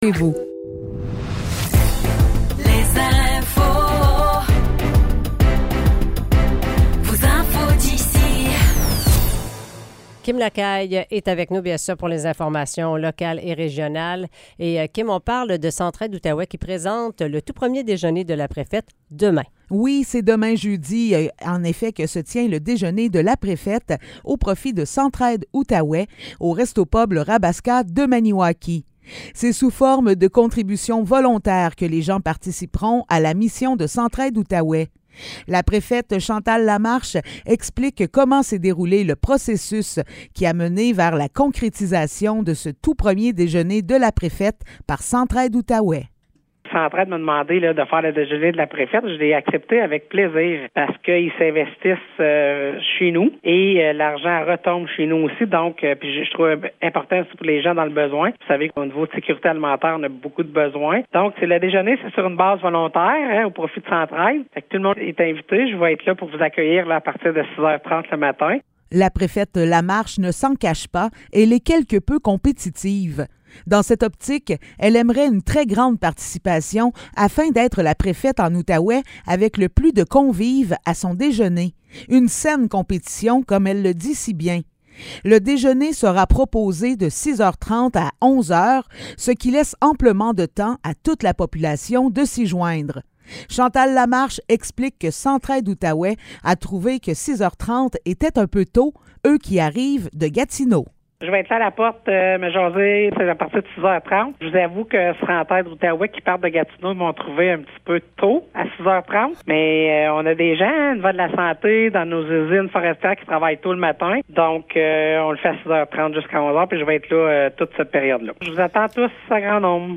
Nouvelles locales - 20 septembre 2023 - 8 h